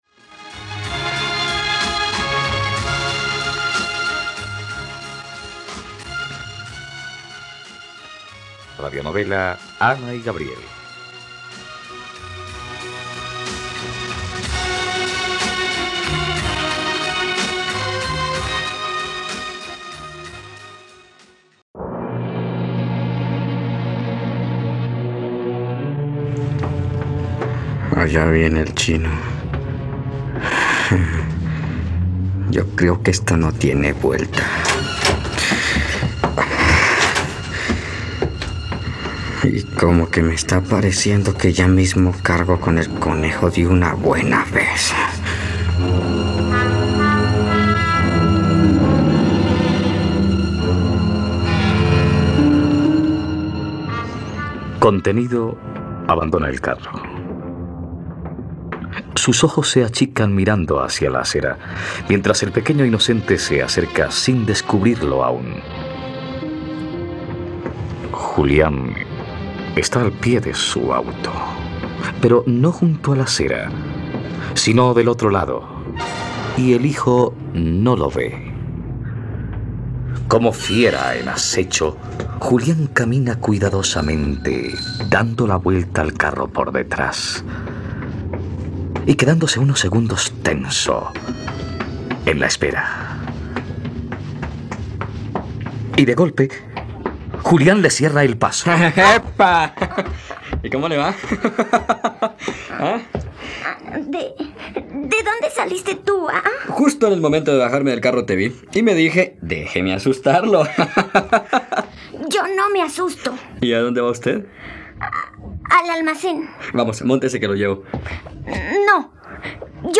..Radionovela. Escucha ahora el capítulo 84 de la historia de amor de Ana y Gabriel en la plataforma de streaming de los colombianos: RTVCPlay.